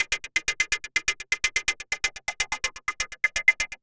Index of /90_sSampleCDs/Transmission-X/Percussive Loops
tx_perc_125_runner.wav